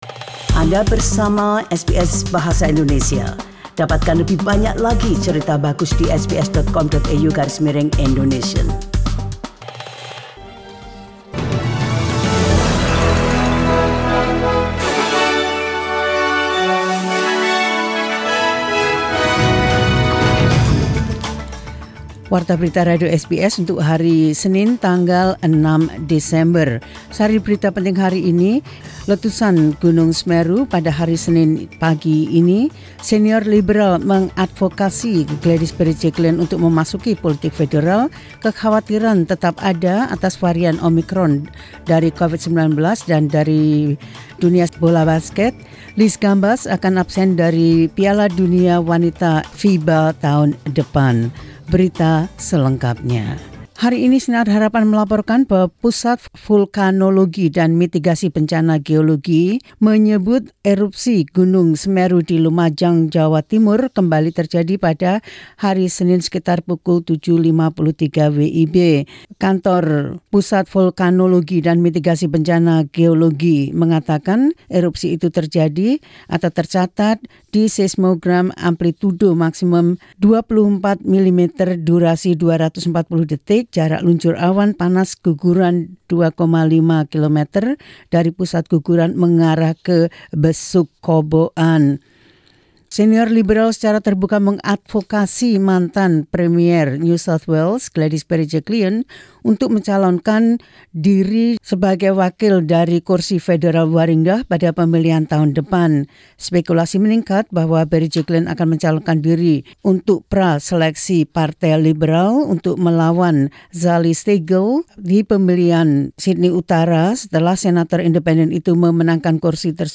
Warta Berita Radio SBS Program Bahasa Indonesia – 06 Des 2021.